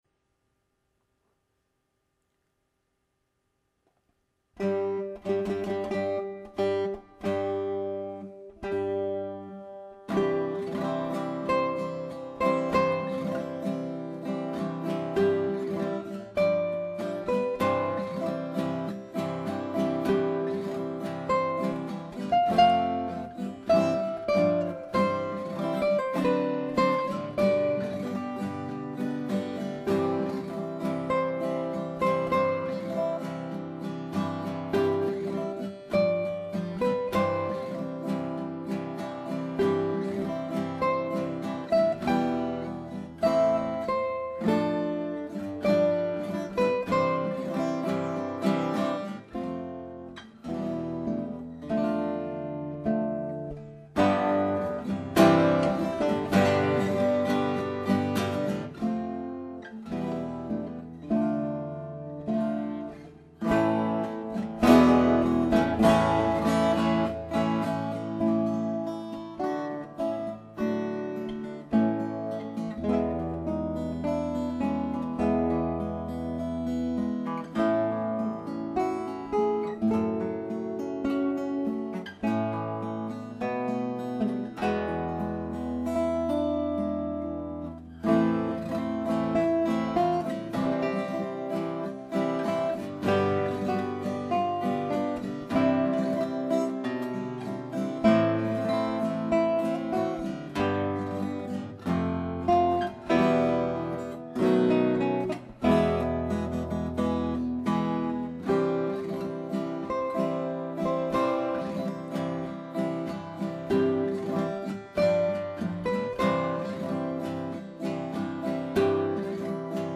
guitar
This husband and wife guitar duo have delighted audiences for over a decade gracing many fine occasions with their sophisticated stylings and extensive repertoire.